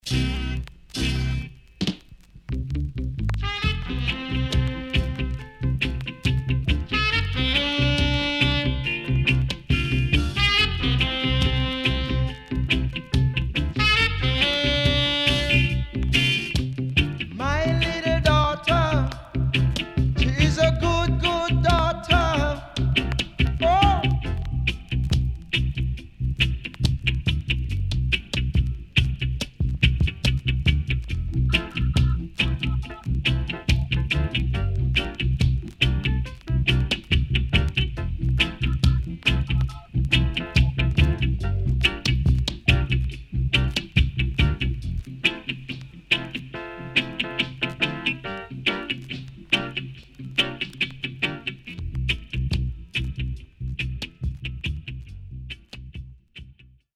CONDITION SIDE A:VG(OK)〜VG+
Great Vocal
SIDE A:所々チリノイズがあり、少しプチノイズ入ります。